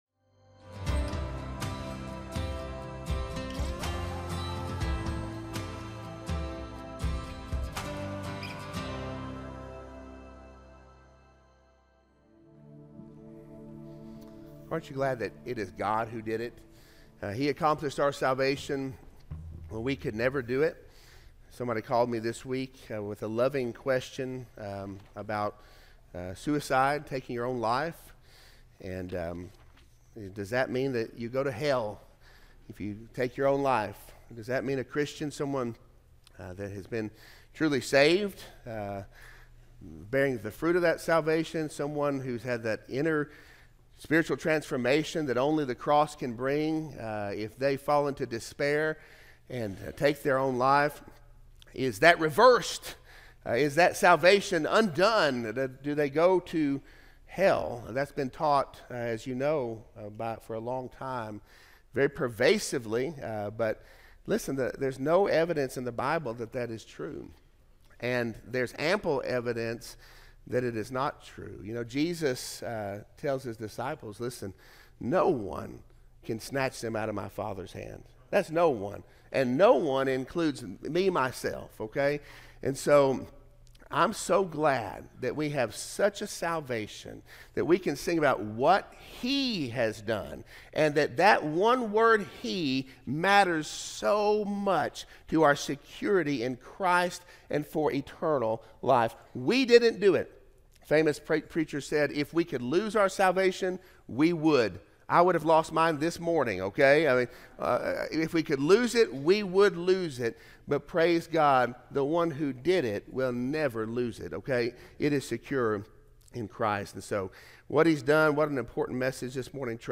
Sermon-1-5-25-audio-from-video.mp3